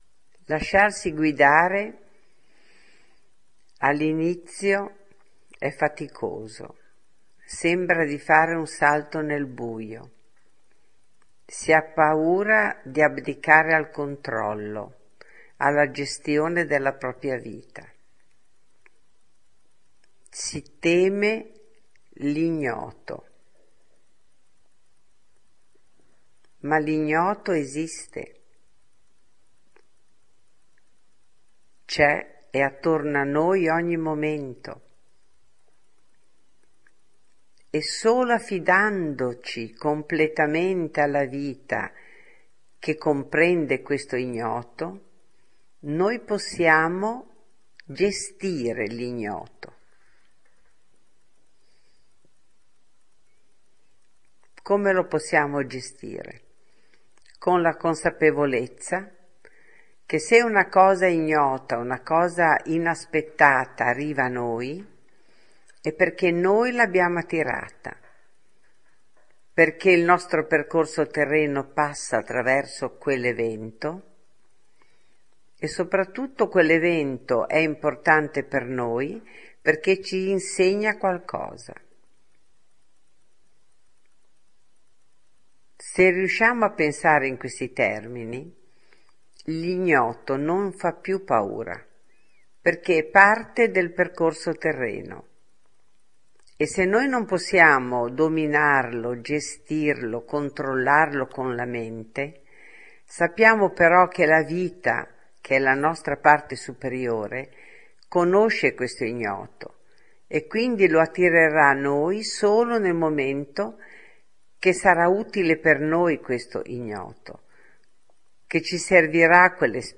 Gestire l’Ignoto – meditazione